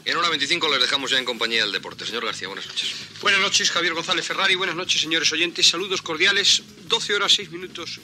Javier González Ferrari dona pas a José María García
Informatiu